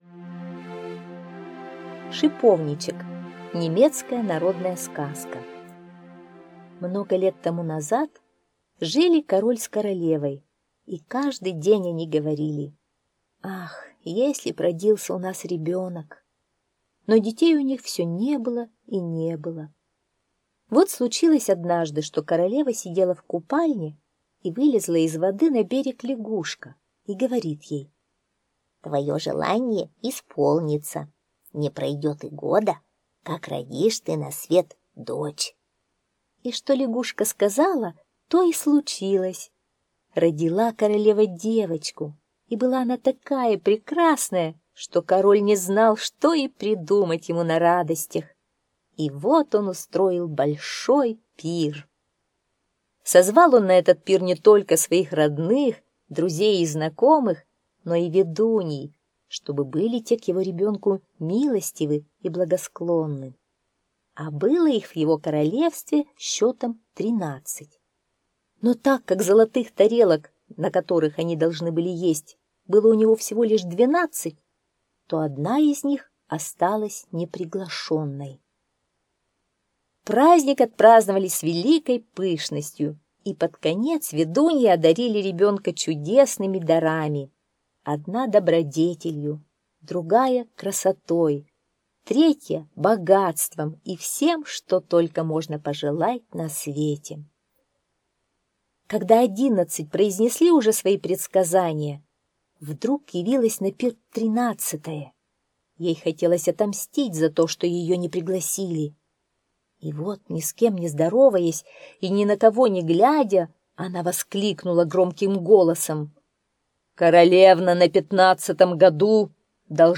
Шиповничек - немецкая аудиосказка - слушать онлайн